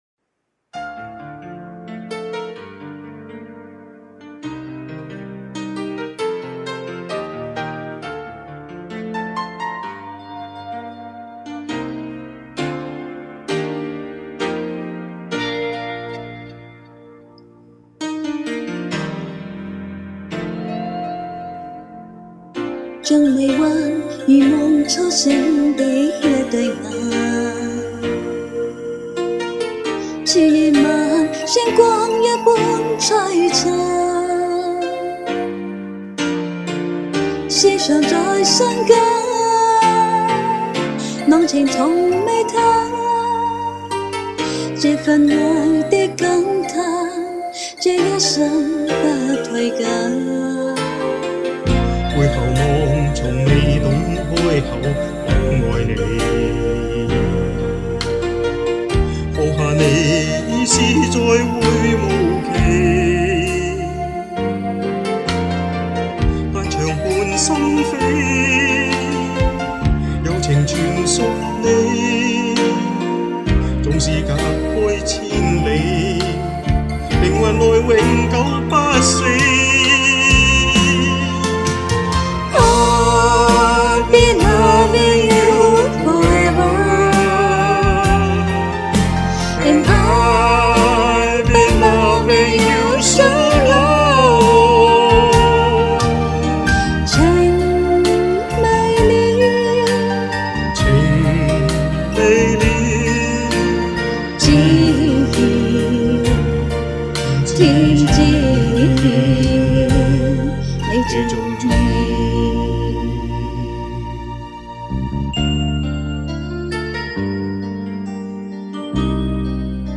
二人唱得好聽，讚!